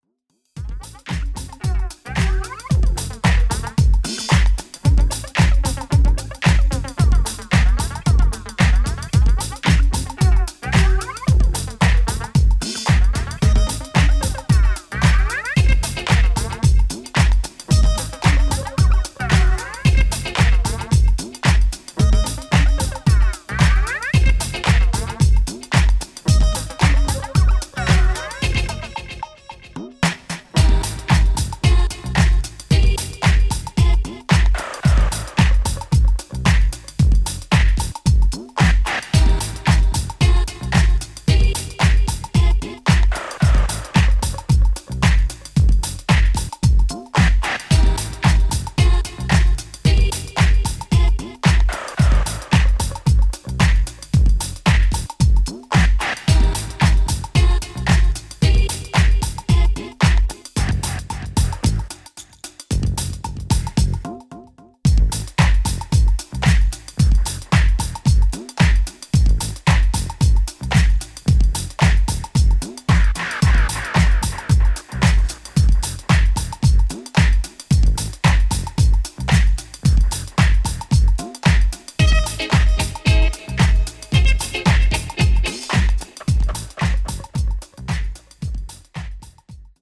ジャンル(スタイル) NU DISCO / DISCO / HOUSE / RE-EDIT